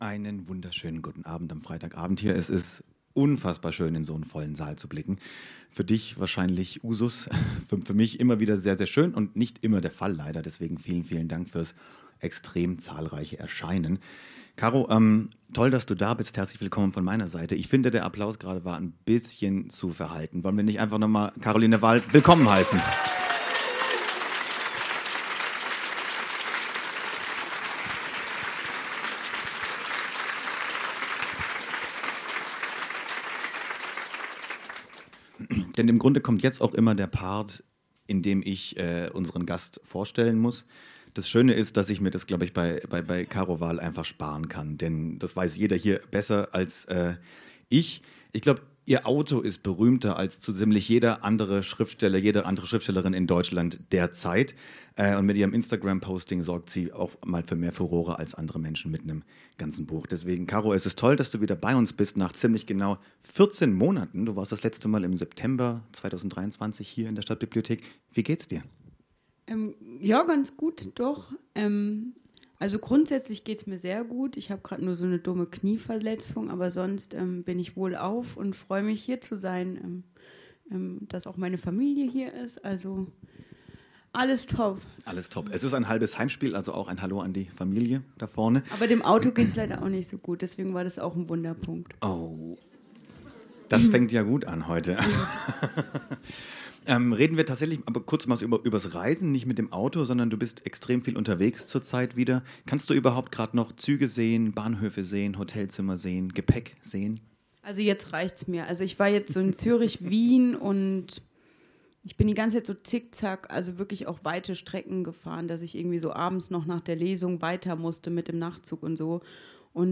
Caroline Wahl Lesung und Gespräch